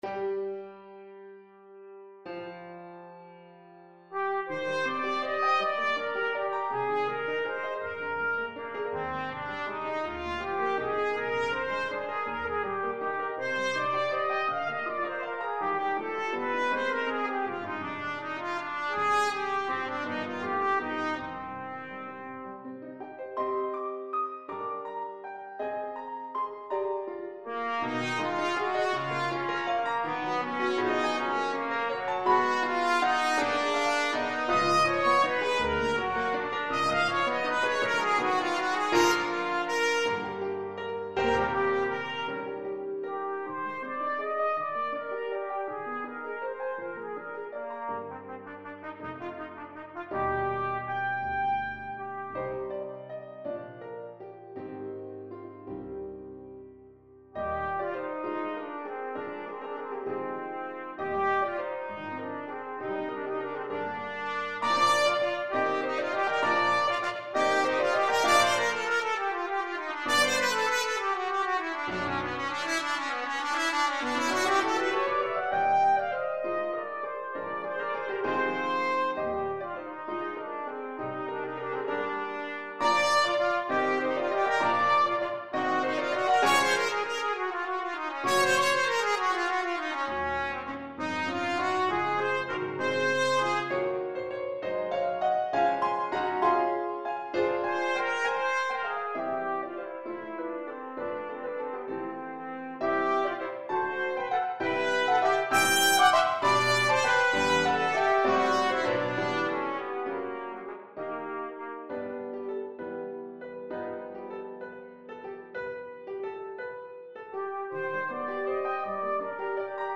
6/8 (View more 6/8 Music)
Classical (View more Classical Trumpet Music)